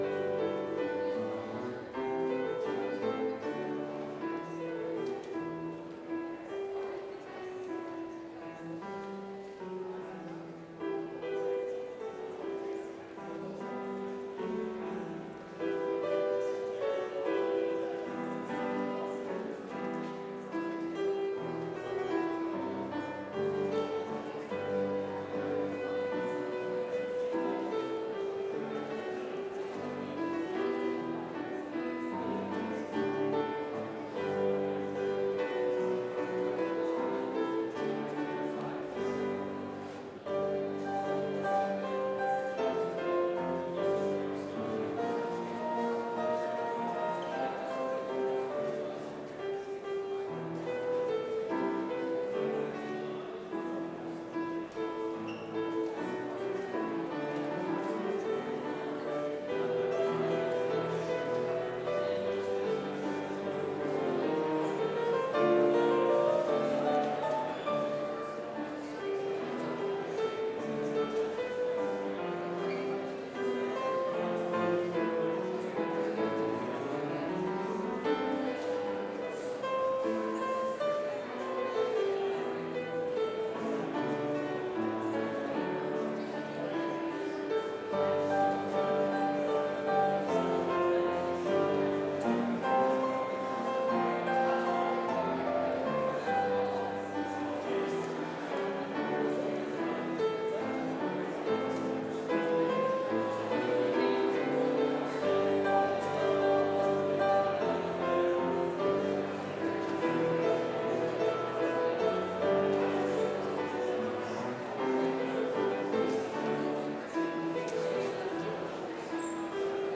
Complete service audio for Chapel - Wednesday, October 29, 2025